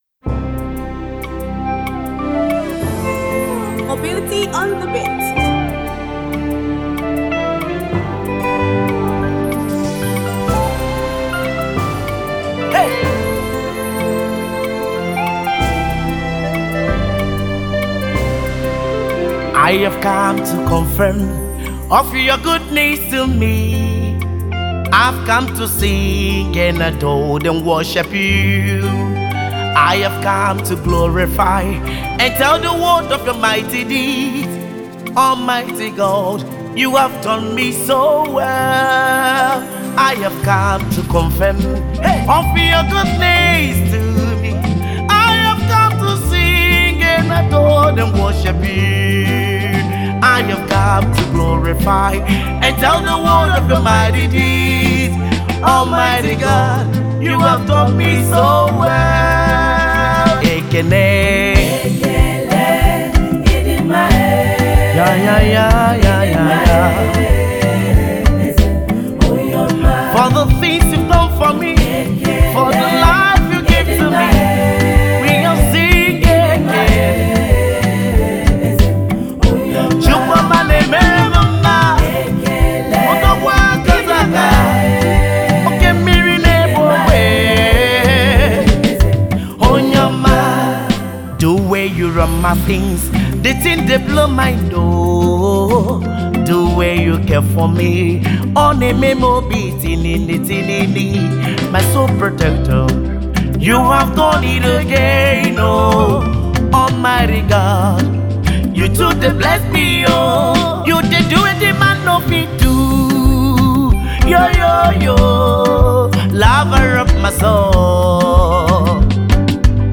Afro beatmusic
Gospel song